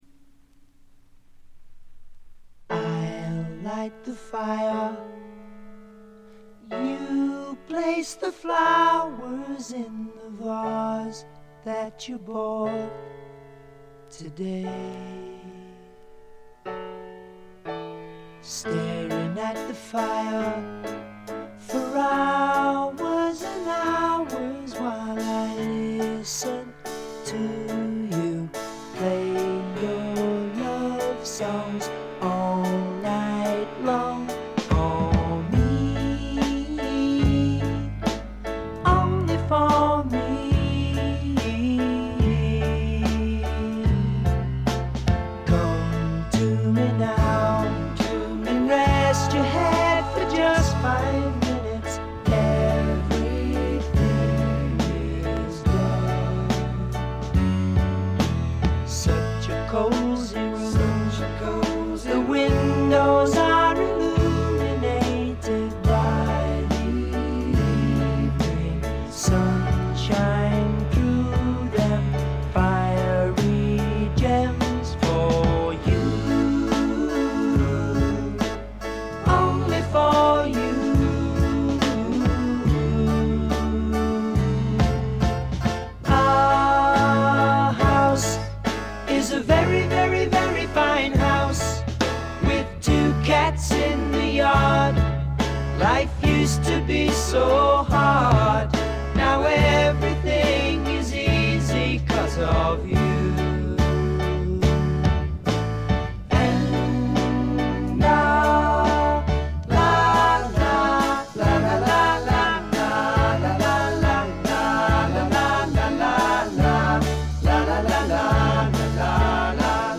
部分試聴ですが静音部での微細なバックグラウンドノイズ程度。
試聴曲は現品からの取り込み音源です。